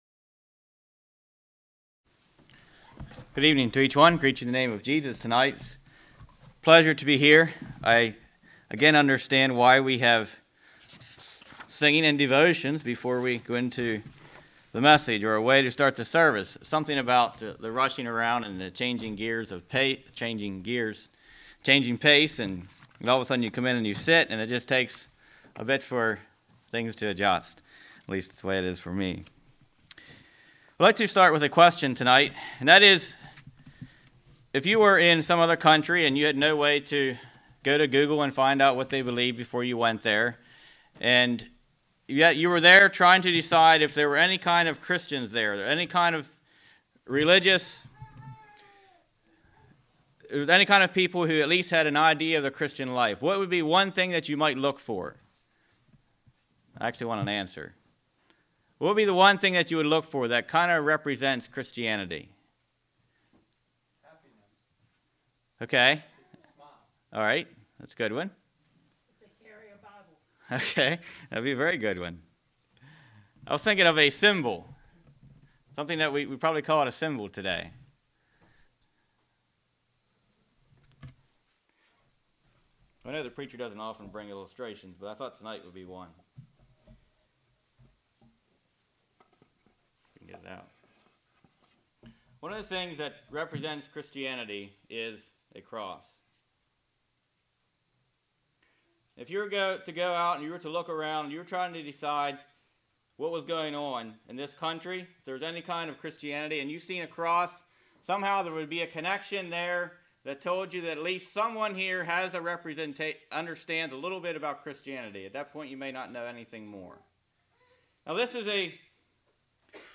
Counsel Meeting sermon that points out 6 ways the Cross speaks to me.